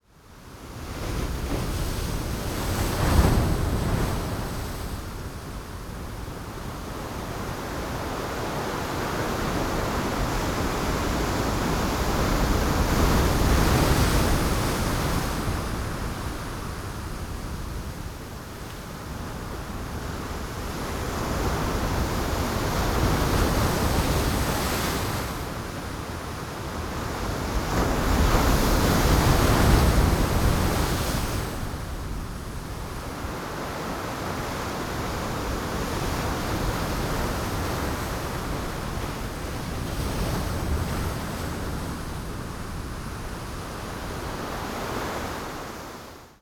As the tidal force increases and decreases, waves of different sizes fade in and out.
• Strong wave:
wave_strong.wav